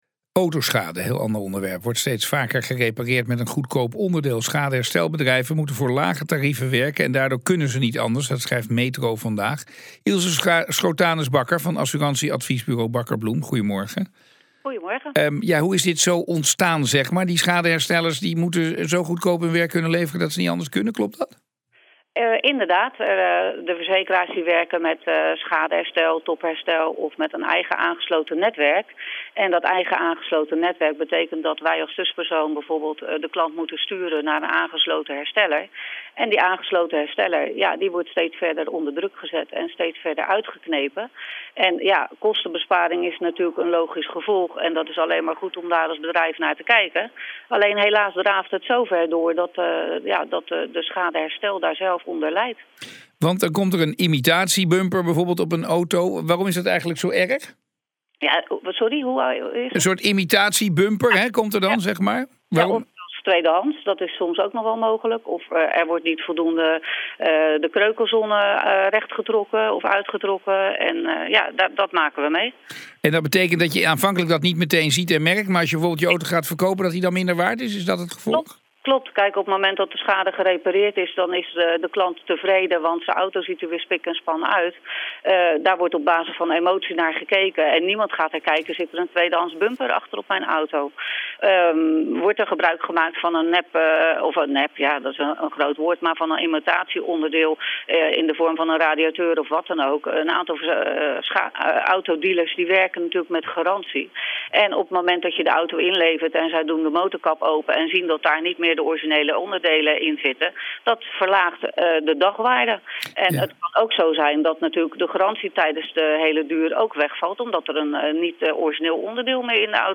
BNR nieuwsradio uitzending
Interview